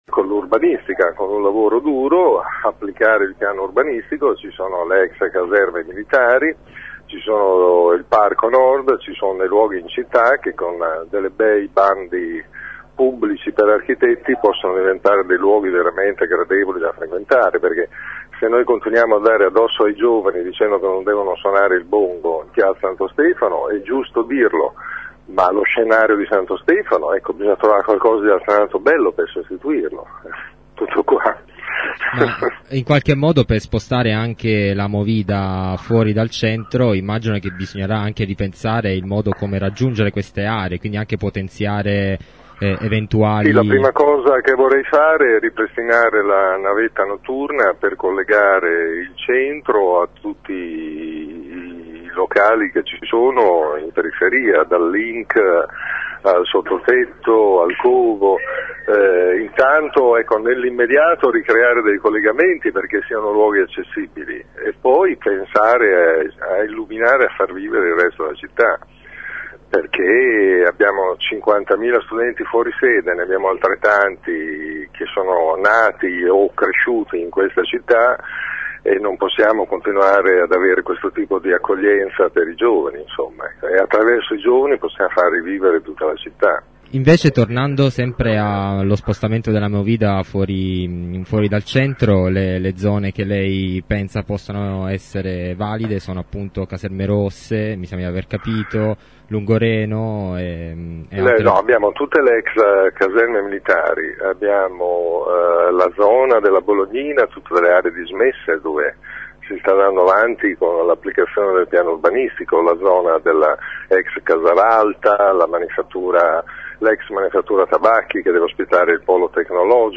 Il candidato sindaco del centro sinistra pensa a tutte le ex caserme, alle aree dismesse della Bolognina e al Parco Nord. Ai nostri microfoni ha spiegato come intende fare:
Ascolta Merola